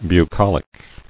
[bu·col·ic]